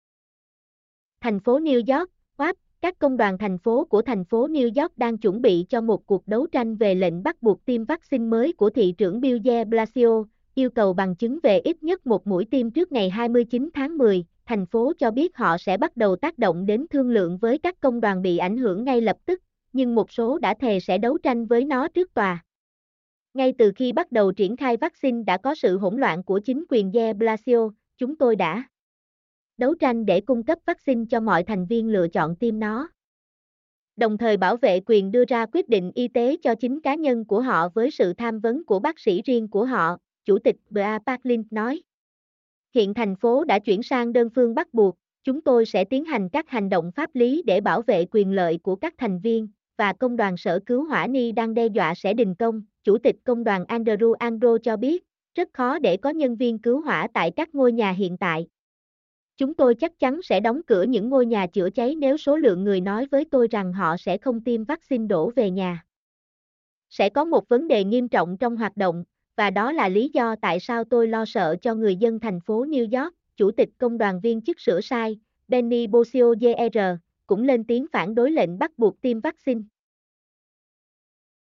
mp3-output-ttsfreedotcom-1.mp3